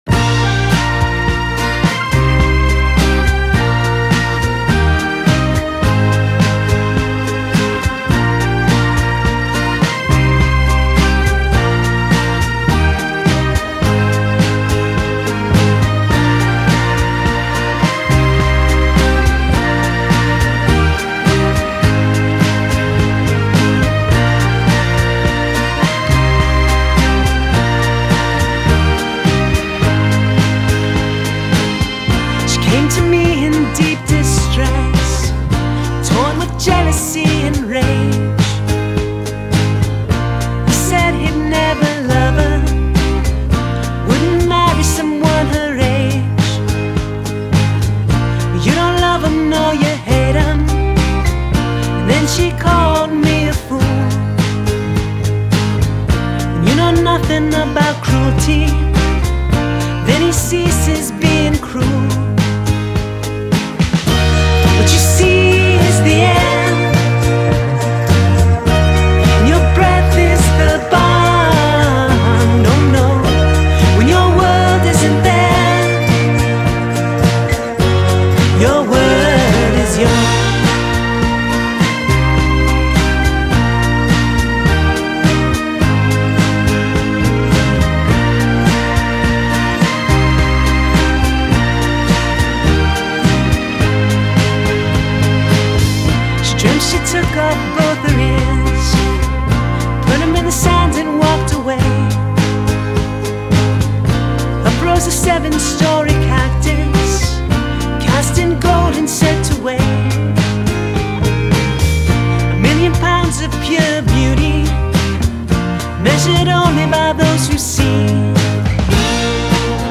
Genre: Indie, Alternative